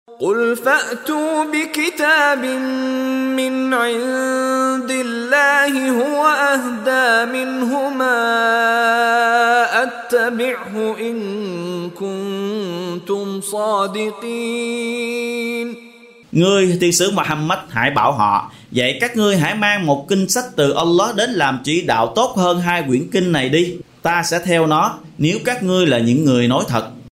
Đọc ý nghĩa nội dung chương Al-Qasas bằng tiếng Việt có đính kèm giọng xướng